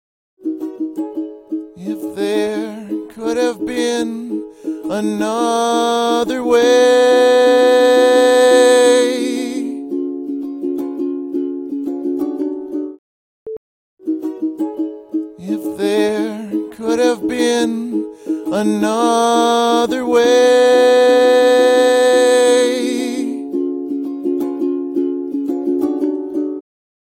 So far, it’s just me singing with my ukulele (Separate tracks).
IMO “another waaAAYY” gets too loud towards the end, can be fixed with audacity’s envelope control … “after” has a hint of reverb and chorus
-The vocal is just raw and dry.